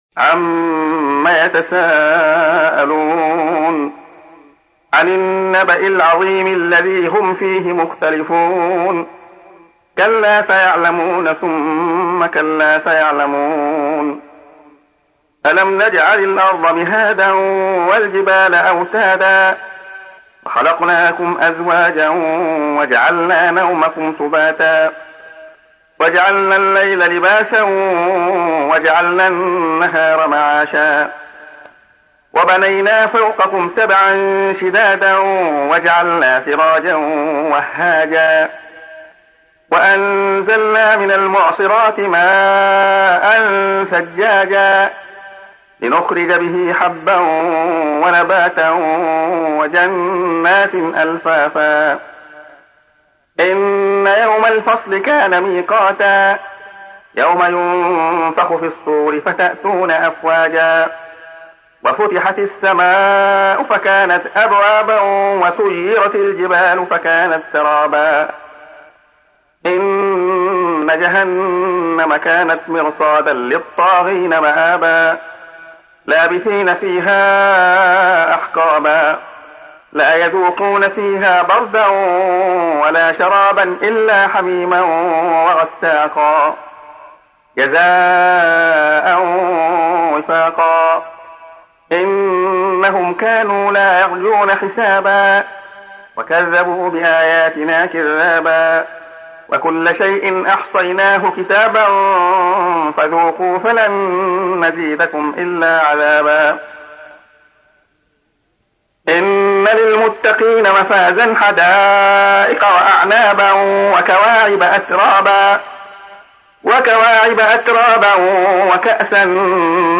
تحميل : 78. سورة النبأ / القارئ عبد الله خياط / القرآن الكريم / موقع يا حسين